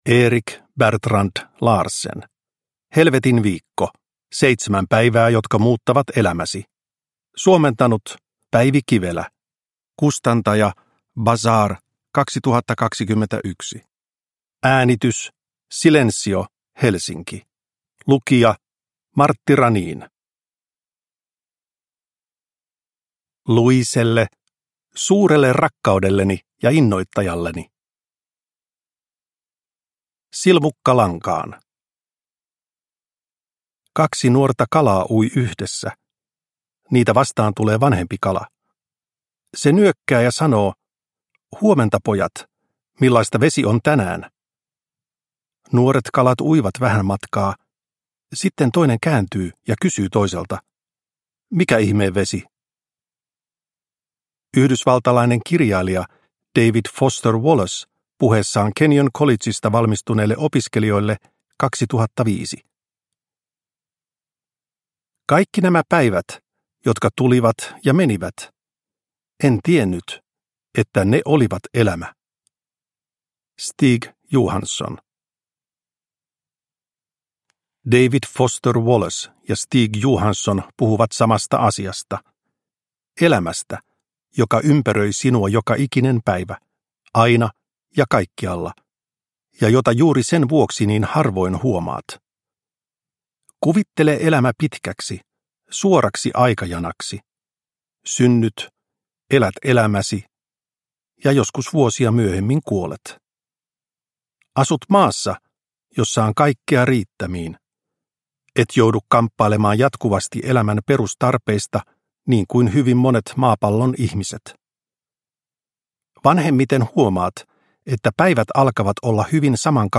Helvetinviikko – Ljudbok – Laddas ner